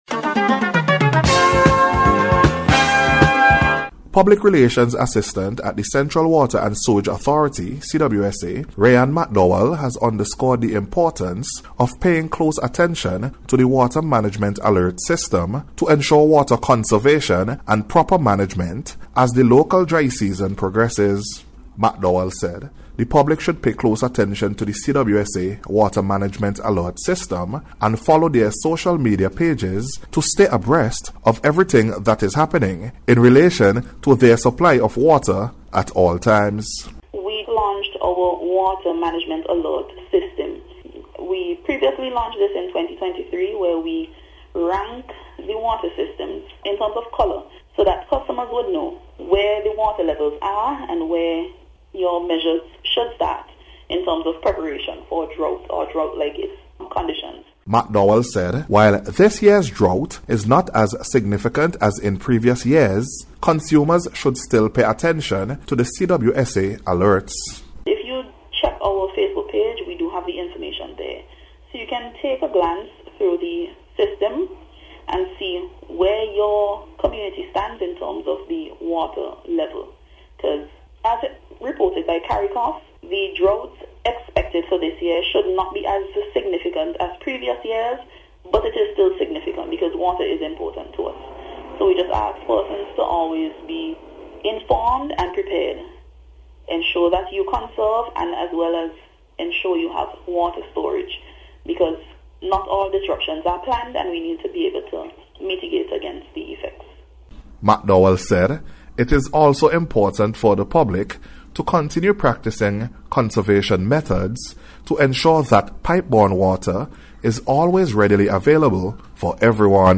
CWSA-WATER-MANAGEMENT-ALERT-SYSTEM-REPORT.mp3